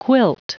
Prononciation du mot quilt en anglais (fichier audio)
Prononciation du mot : quilt